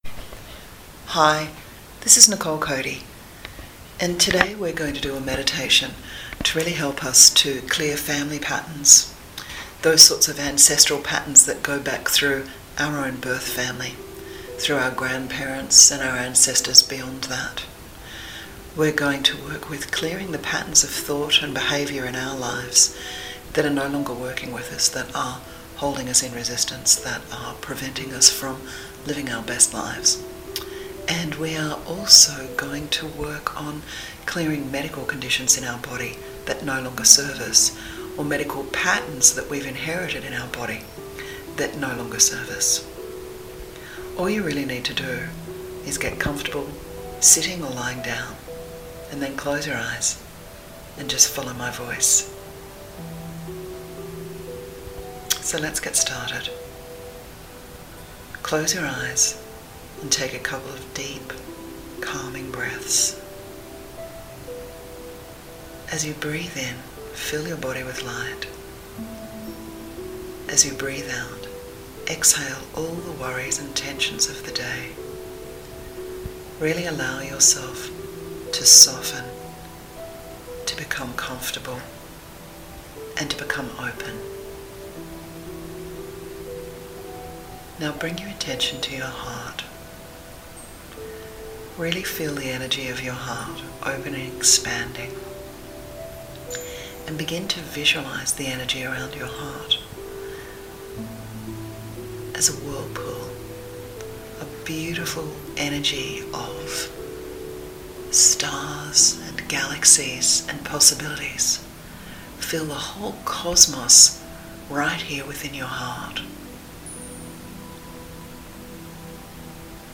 Guided Meditation To Help You Clear Old Emotions And Welcome New Flow